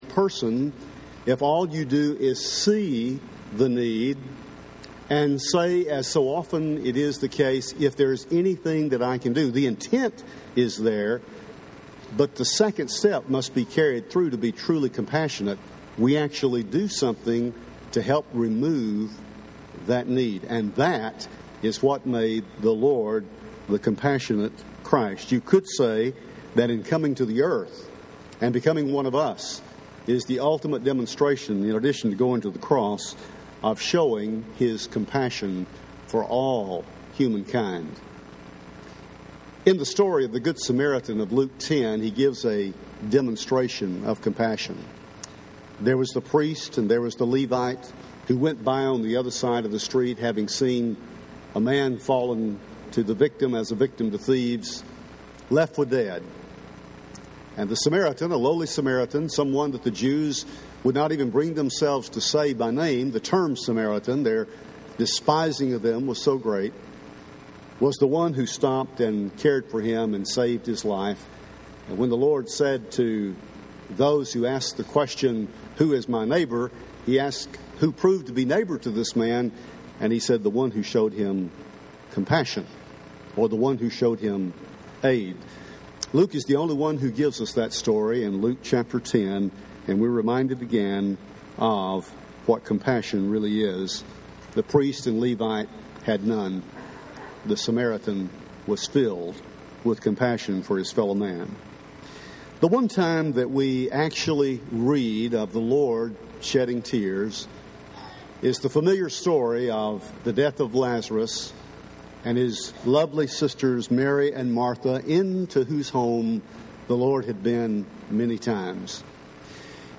The Compassionate Christ – Henderson, TN Church of Christ